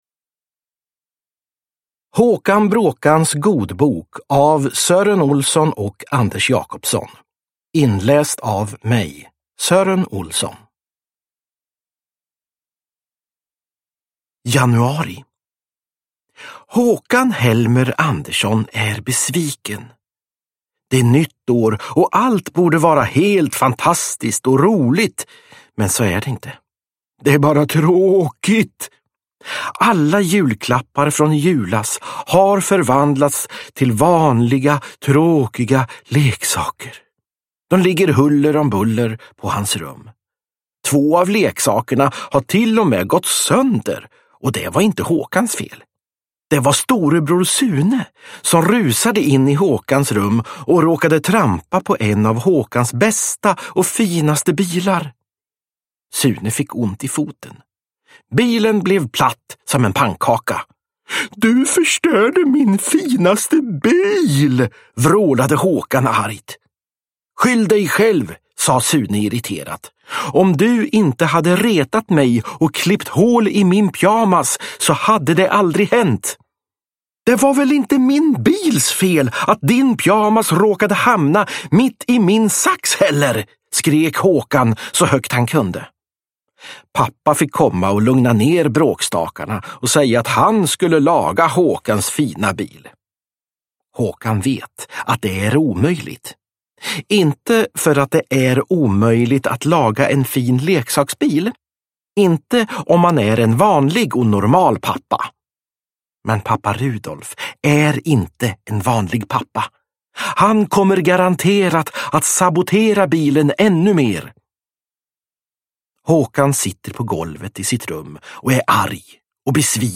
Håkan Bråkans godbok – Ljudbok – Laddas ner
Uppläsare: Sören Olsson